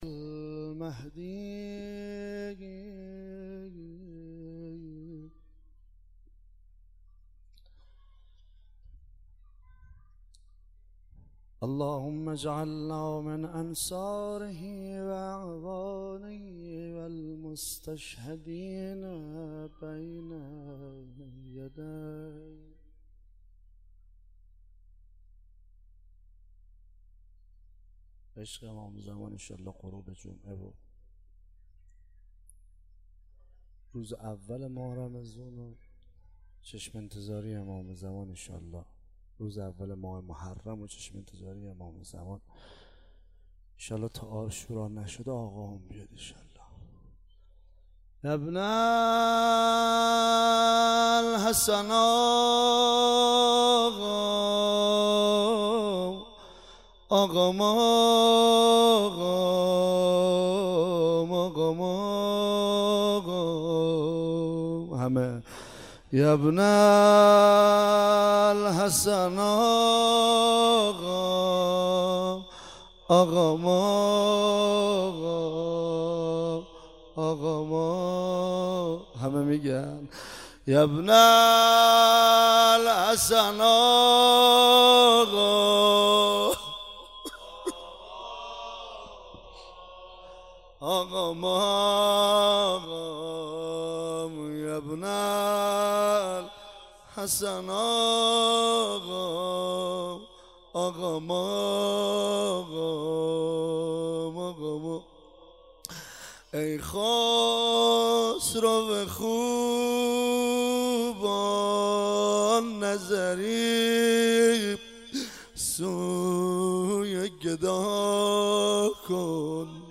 شب دوم محرم 96 - روضه